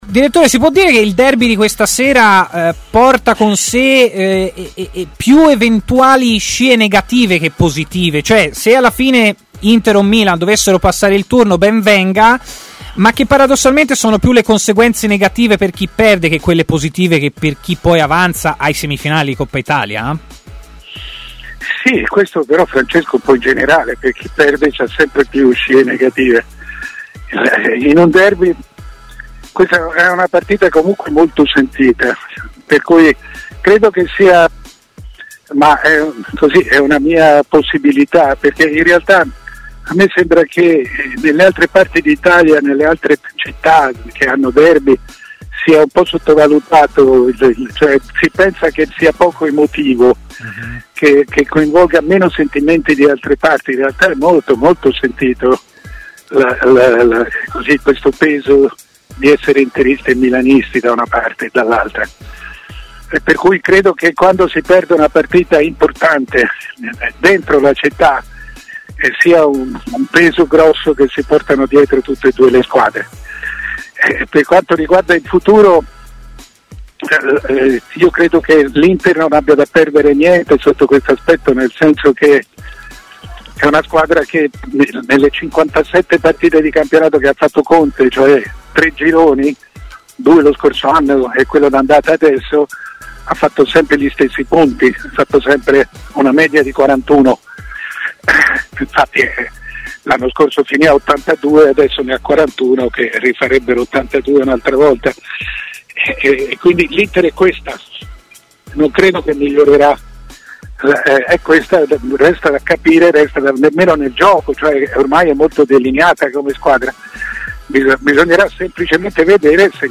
Intervenuto ai microfoni di 'TMW Radio' il giornalista Mario Sconcerti ha parlato del derby di Coppa Italia in programma tra pochi minuti.